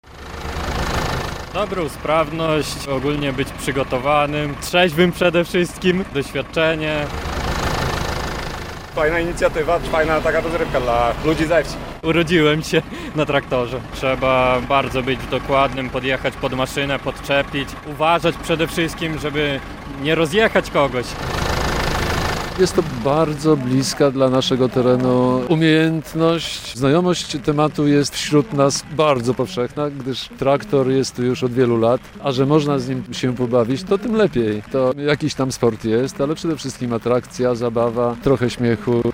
Traktoriada - relacja